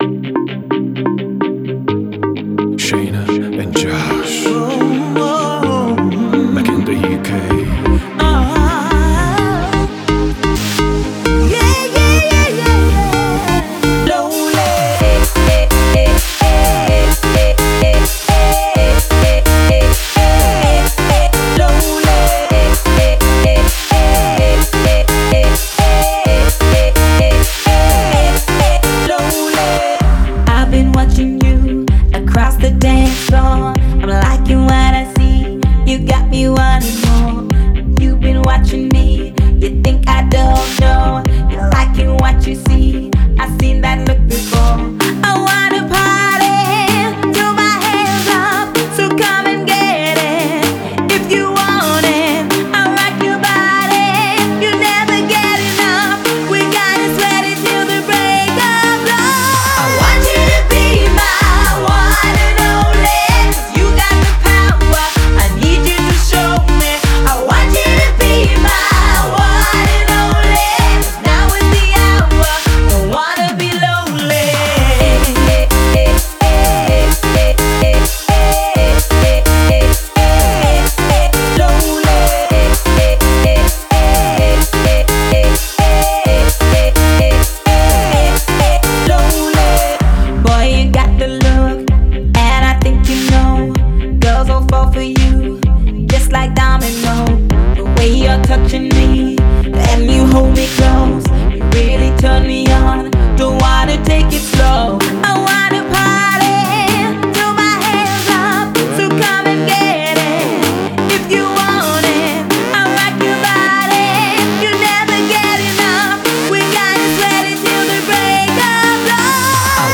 egy igazi könnyed pop sláger